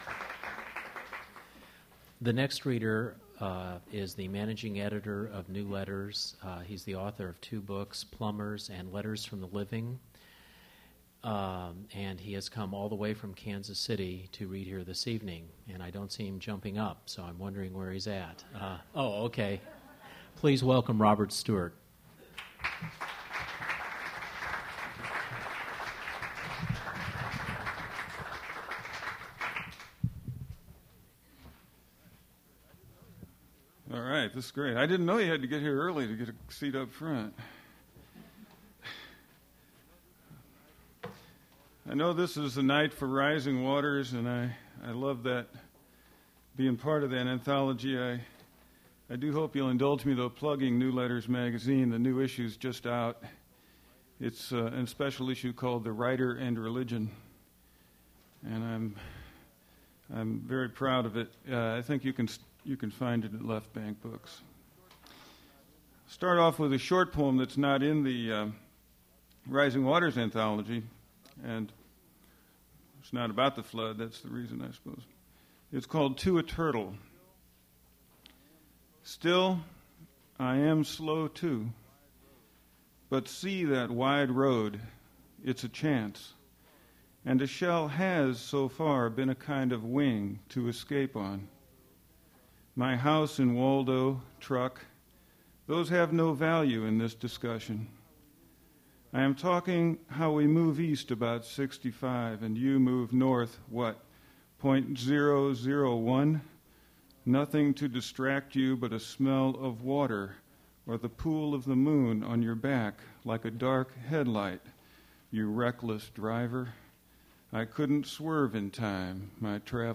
Poetry reading
Rising Waters - Missouri Poets on the Flood. Recording Index: To a Turtle 01:07; Keeping Up 02:04; Coming Up on the River 04:21; Levies 06:01; Baptiste Bakery 08:25.
mp3 edited access file was created from unedited access file which was sourced from preservation WAV file that was generated from original audio cassette.